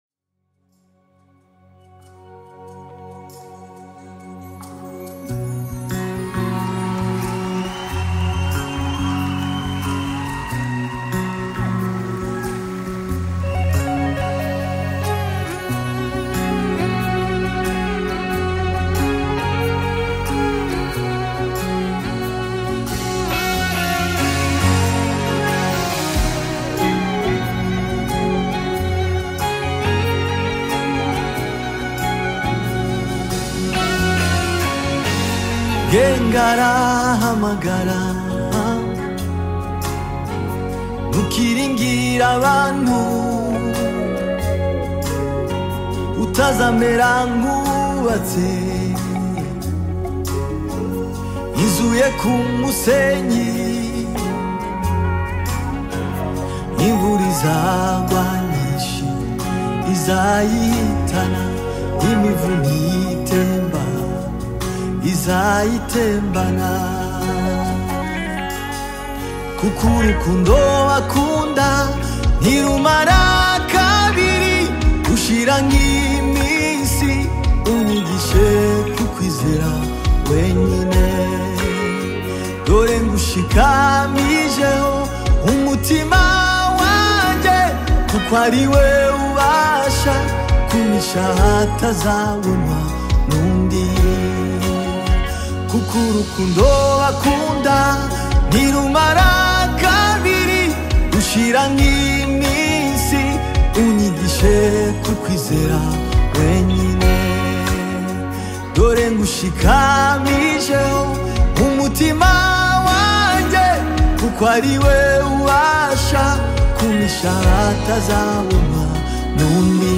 Nyimbo za Dini Kinyarwanda music
Kinyarwanda Gospel music track